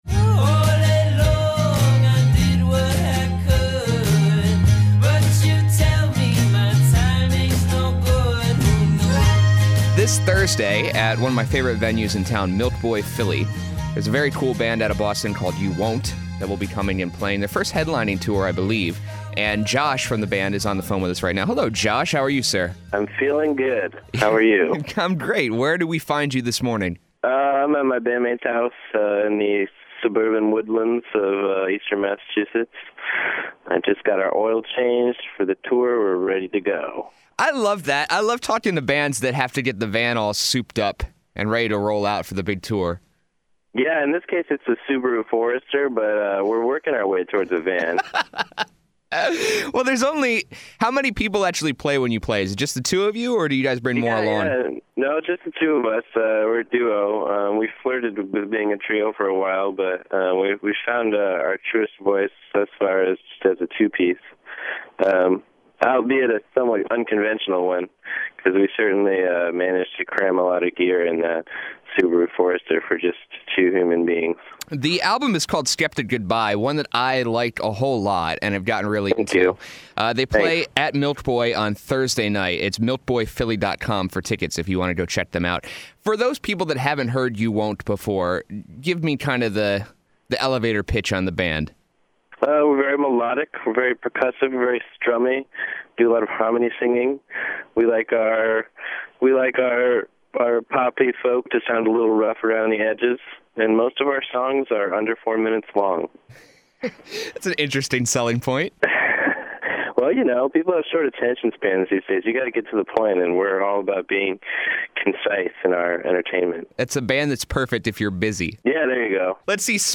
Interview: You Won’t